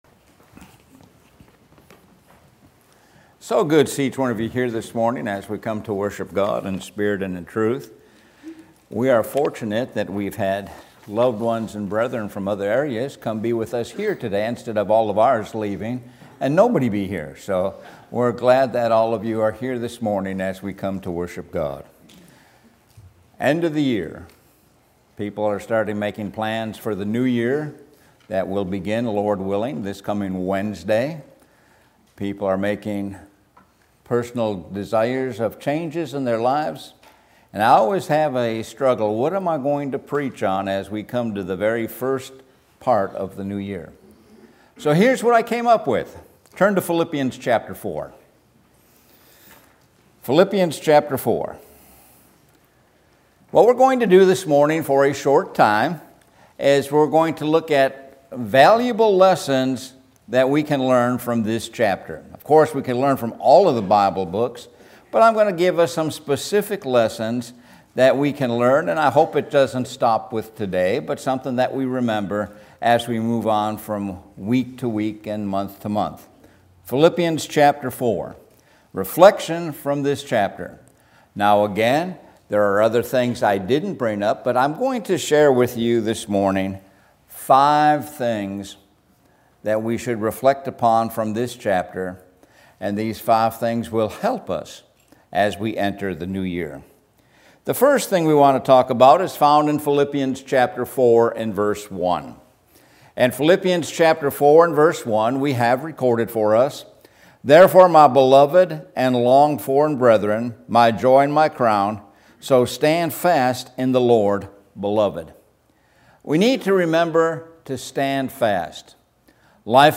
Sun AM Sermon- Stand Fast